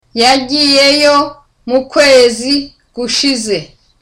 Dialogue
(Smiling too.)